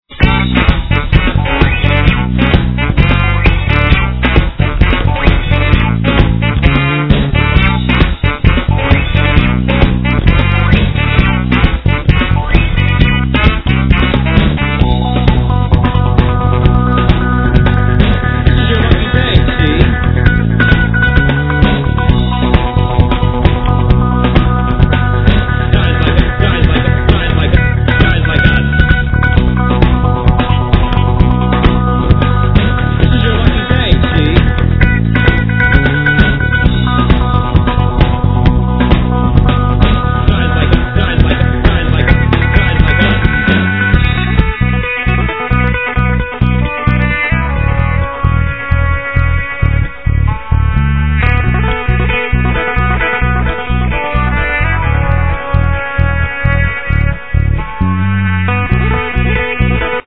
Guitar
Drums
Sax
Bass